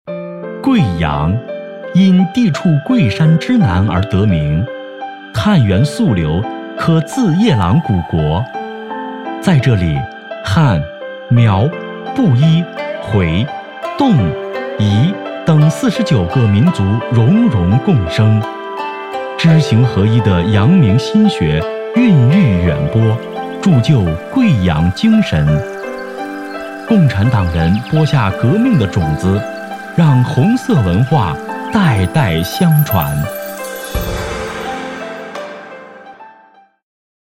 【男80号专题】贵山贵水迎贵客
【男80号专题】贵山贵水迎贵客.mp3